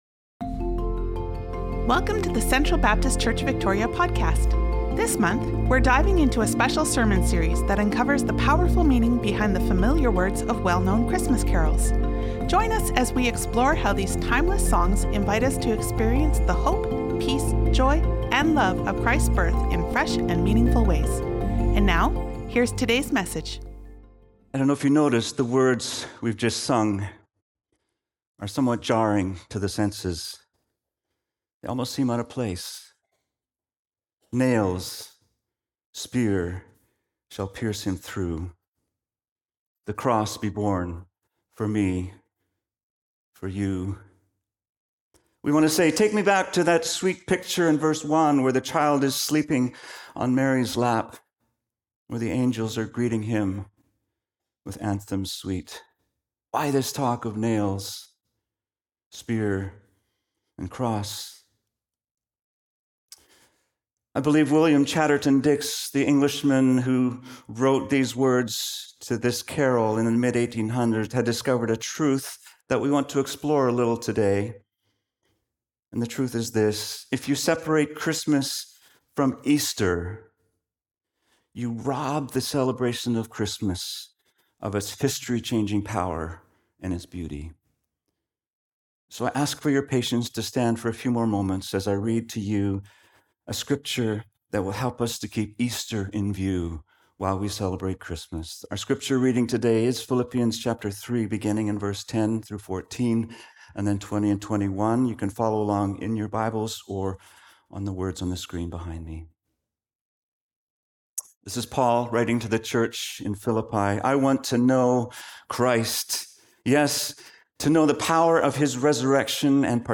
Philippians 3:10-21 Sermon